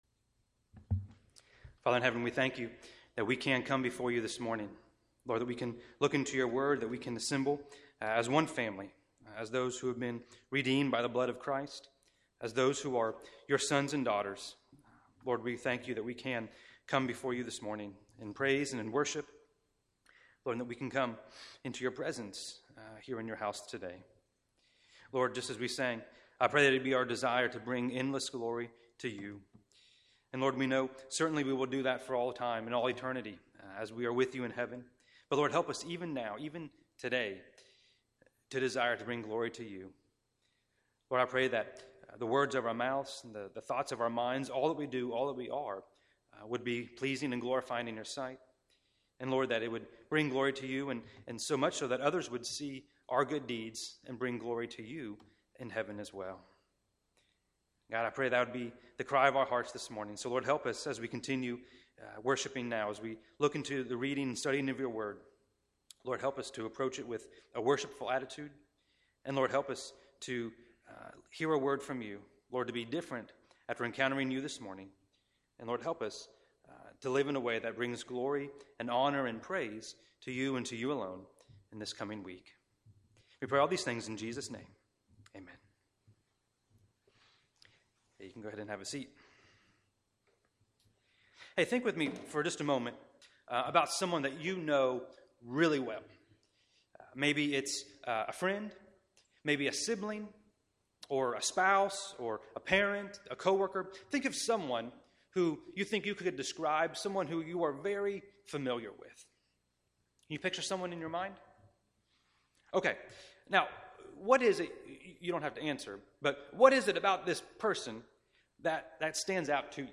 Past Sermons - Chinese Baptist Church of Miami